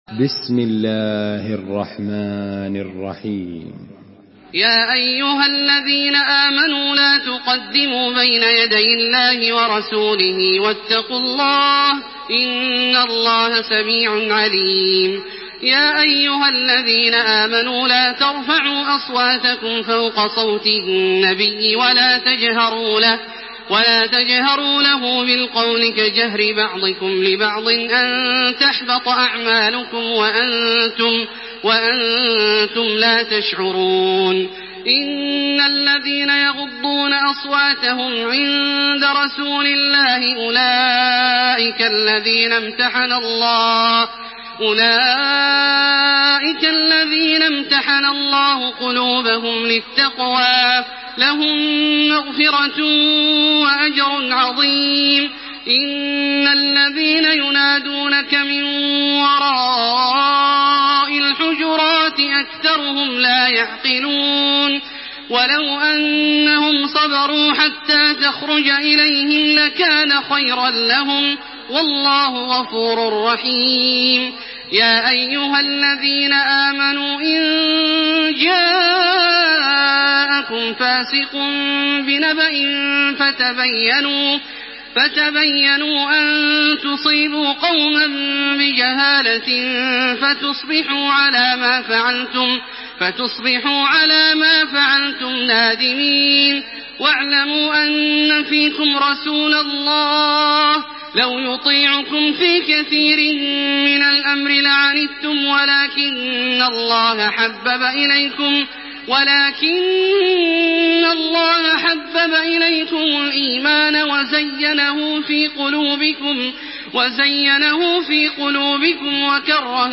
Listen and download the full recitation in MP3 format via direct and fast links in multiple qualities to your mobile phone.
تراويح الحرم المكي 1428
مرتل